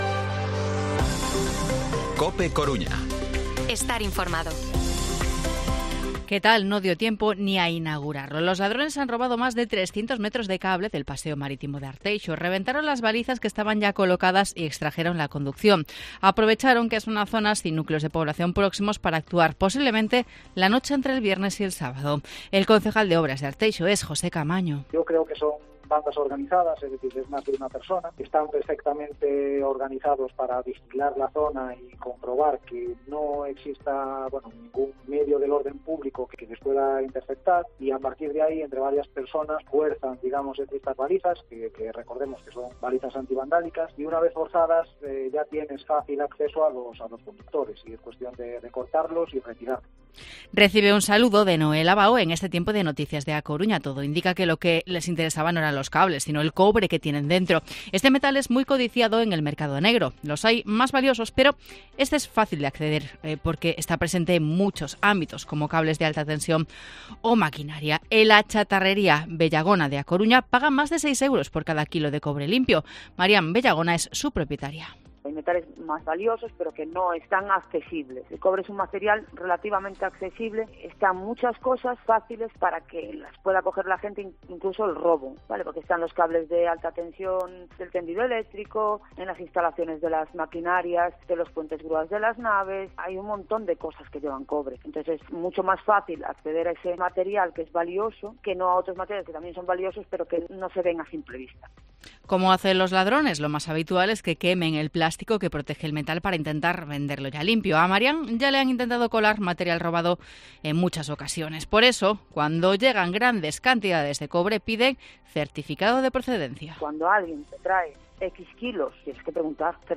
Informativo Mediodía COPE Coruña martes, 17 de octubre de 2023 14:20-14:30